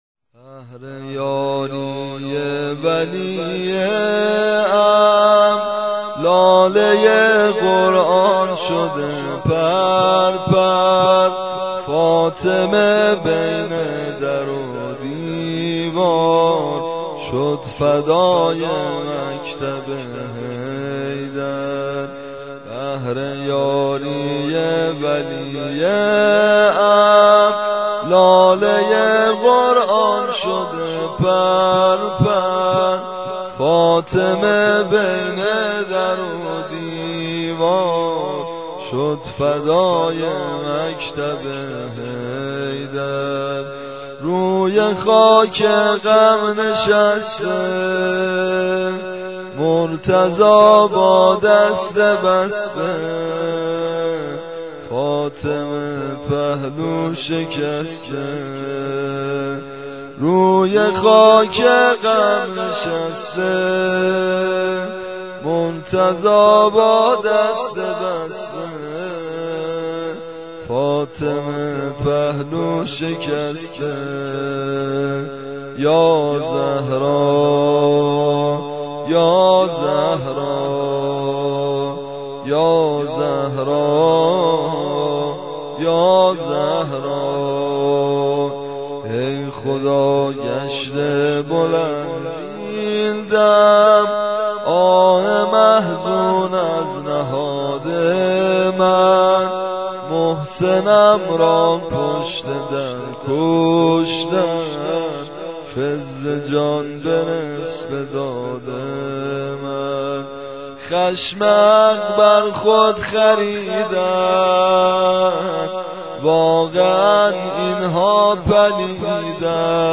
صوت / قابل توجه مداحان جوان / نوحه پیشنهادی (2) + شعر